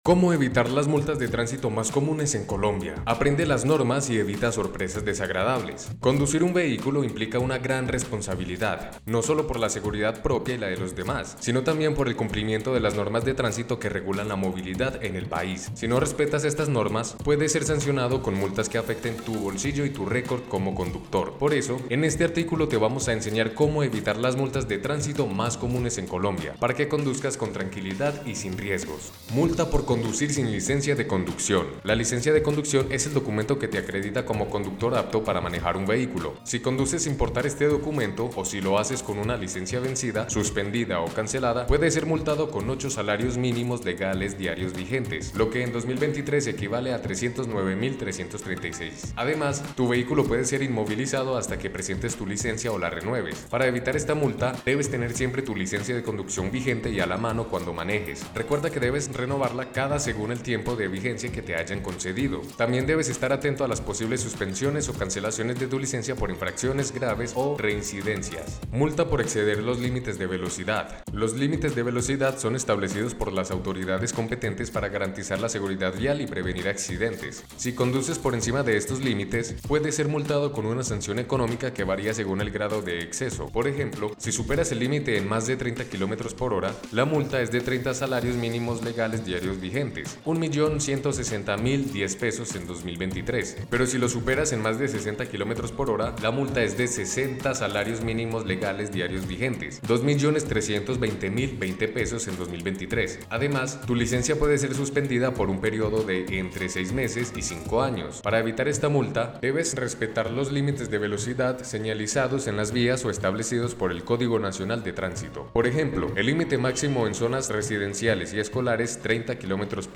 No te preocupés, acá te lo leemos.